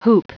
Prononciation du mot hoop en anglais (fichier audio)
Prononciation du mot : hoop